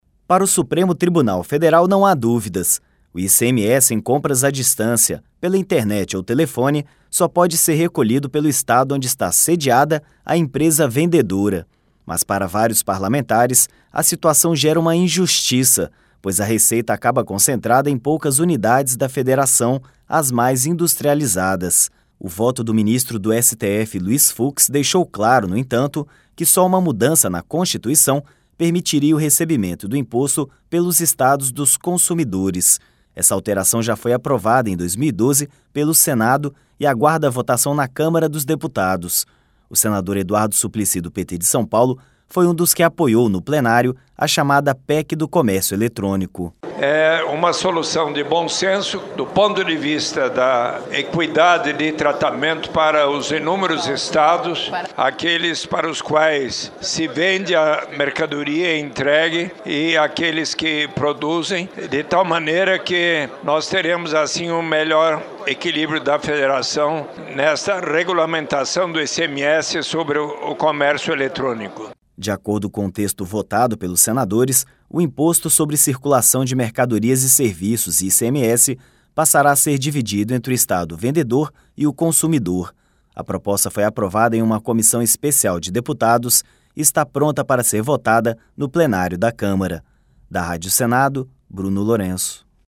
O senador Eduardo Suplicy, do PT de São Paulo, foi um dos que apoiou no plenário a chamada PEC do Comércio Eletrônico.